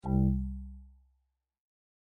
Батарея почти разряжена (звуковой сигнал)